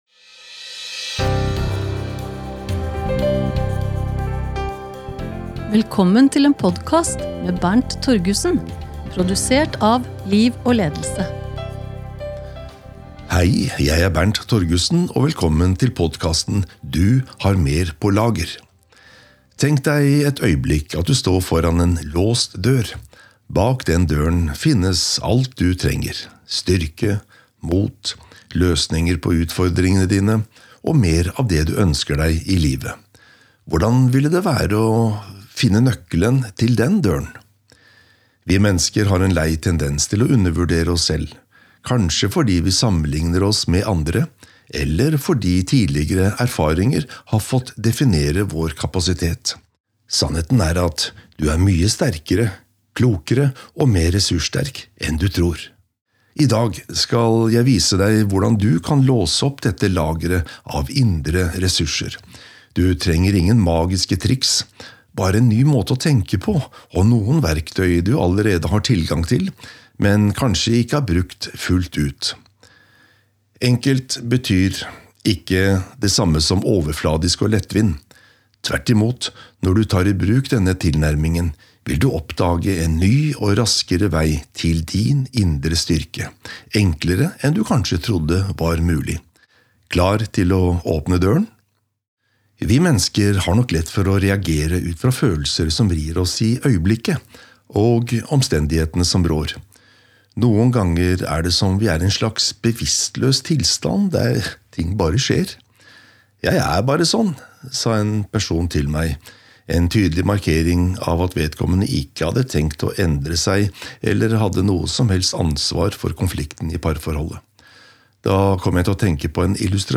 Lydbok: Du har mer på lager